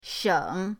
sheng3.mp3